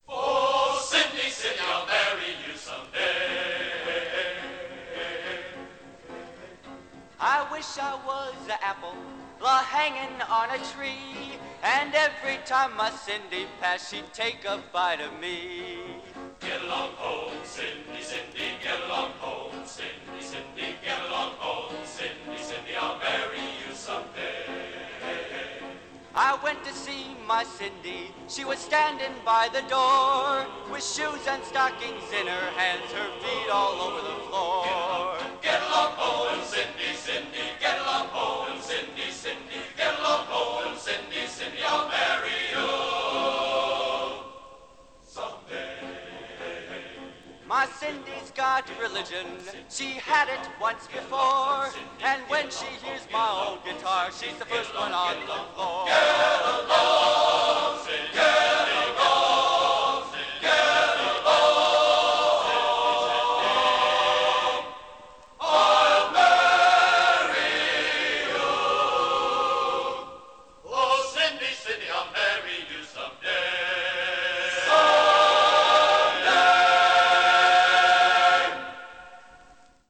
Genre: | Type: Featuring Hall of Famer |Studio Recording